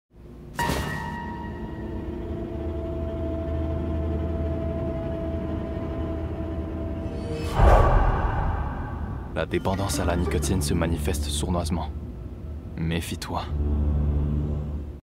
Publicité (RSEQ) - FR